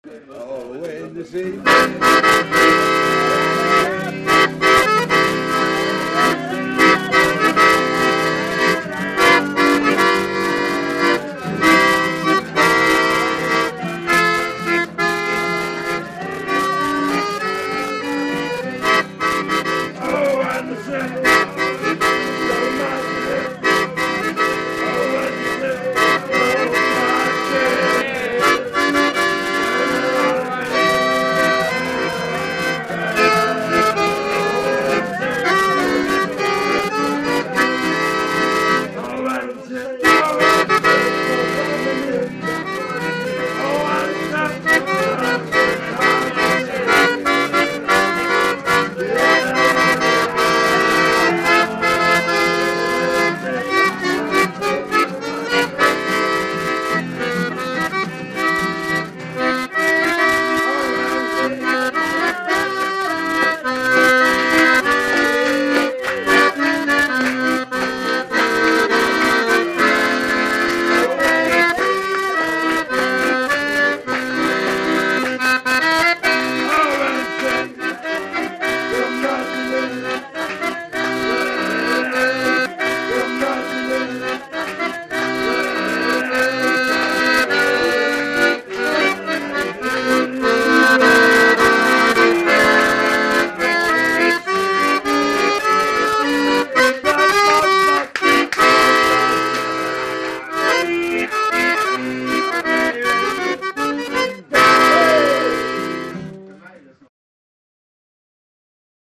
Her har vi et amatøropptak
Varierende og allsidig fest og danse -musikk.